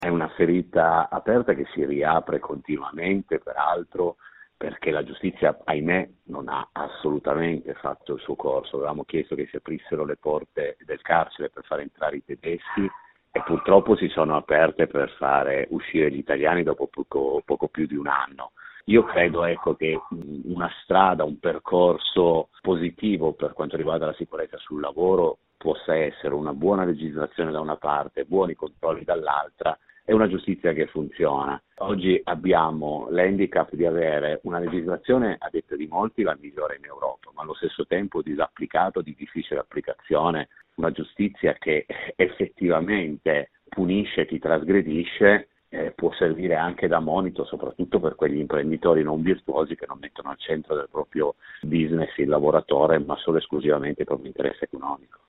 Antonio Boccuzzi è l’unico sopravvissuto alla strage: